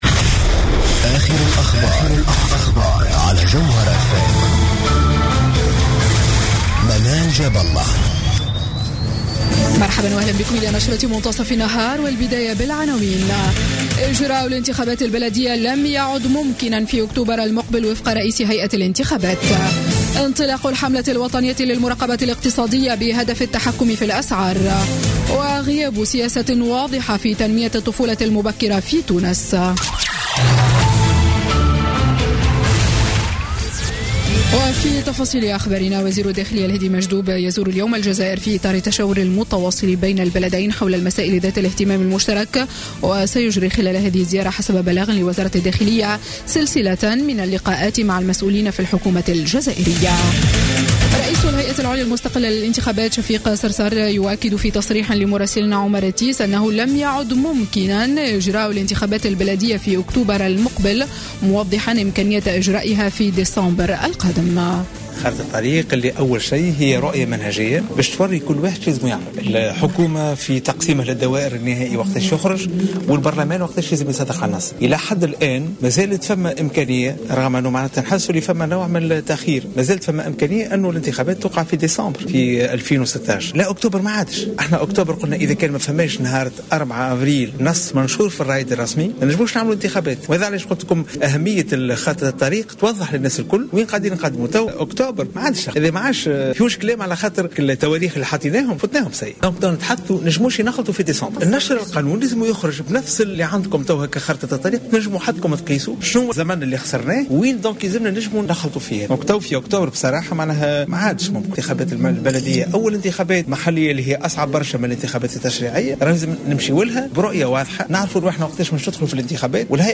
نشرة أخبار منتصف النهار ليوم الخميس 24 مارس 2016